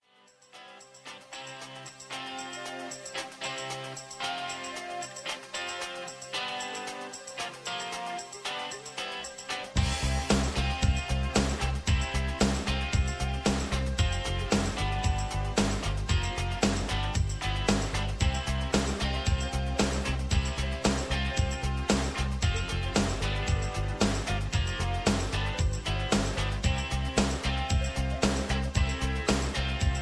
Karaoke MP3 Backing Tracks
Just Plain & Simply "GREAT MUSIC" (No Lyrics).
karaoke mp3 tracks